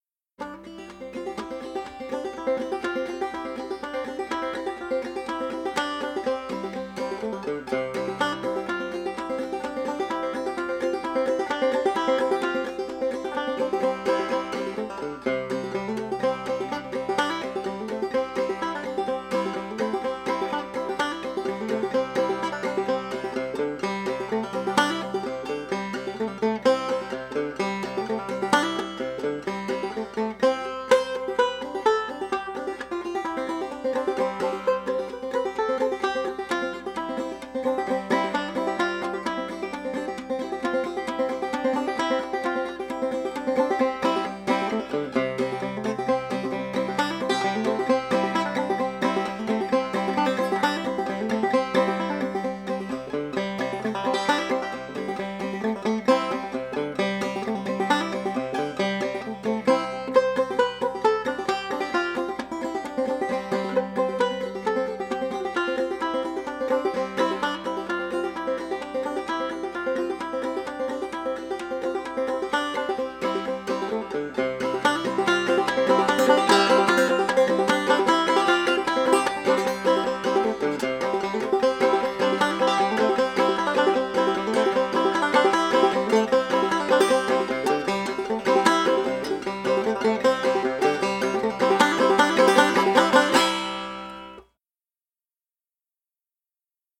bluegrass
banjo